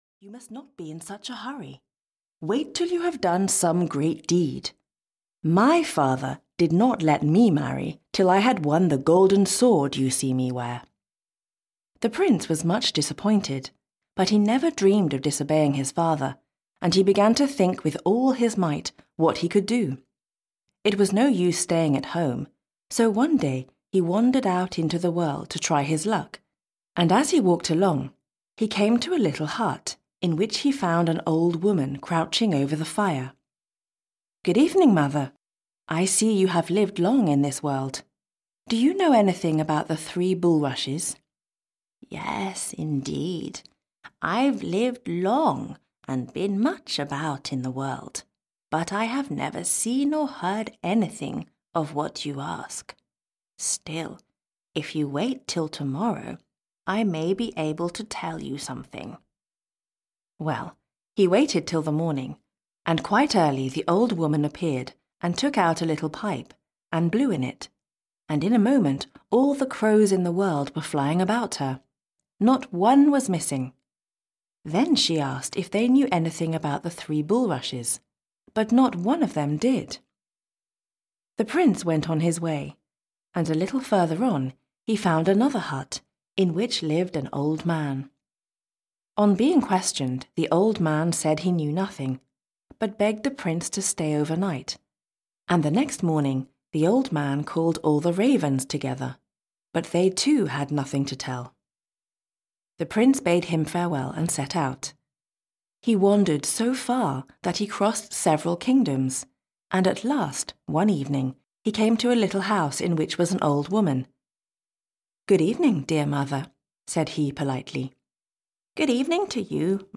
Stories from All over the World (EN) audiokniha
Ukázka z knihy